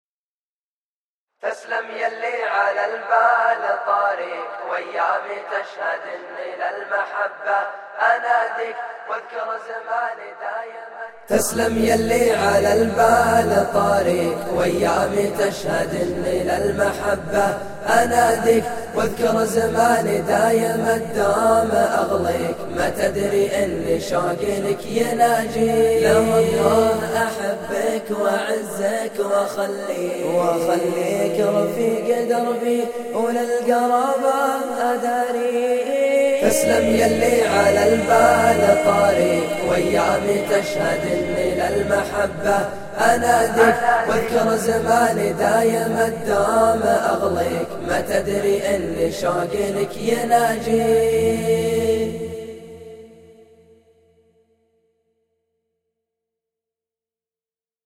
الأنشودة تجنن